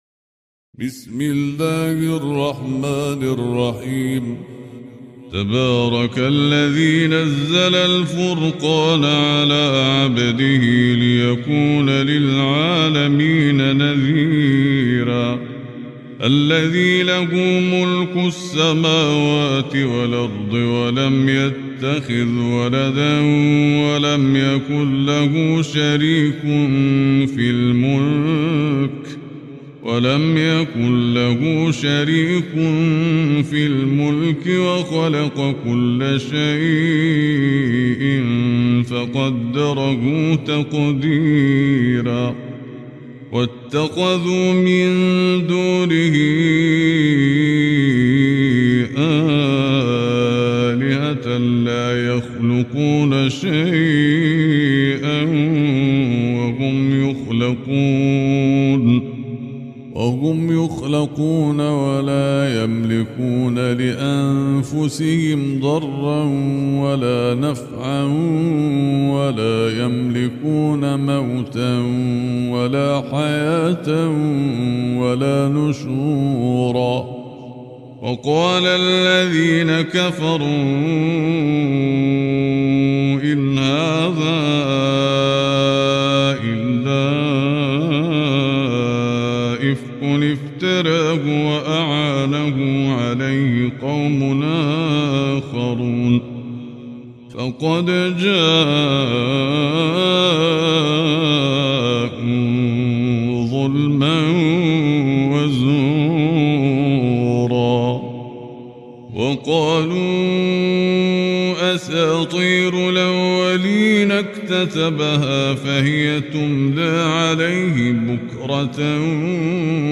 القرآن الكريم - تلاوة وقراءة بصوت أفضل القراء